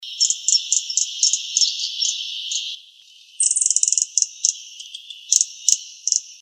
Gilded Sapphire (Hylocharis chrysura)
Location or protected area: Colonia Carlos Pellegrini
Condition: Wild
Certainty: Recorded vocal
Recs.Picaflor-bronceado.mp3